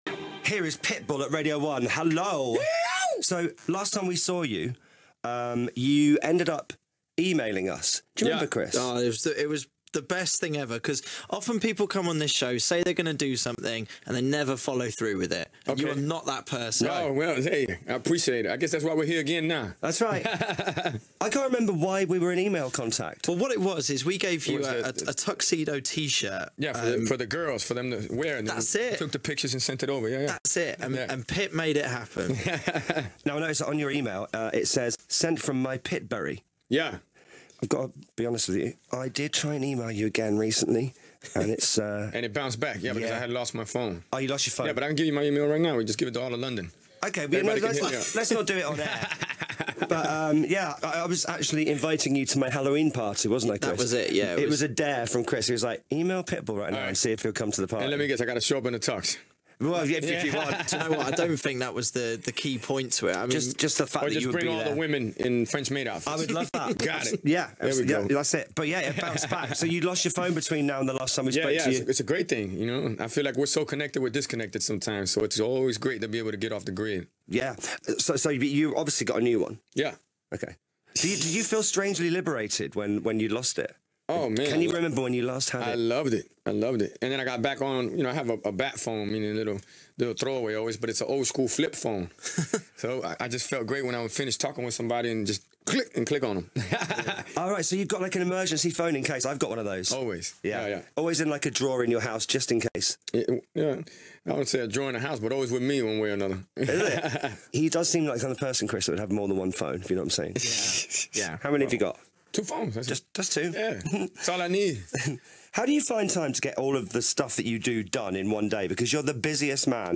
bbc-radio-1-breakfast-show.m4a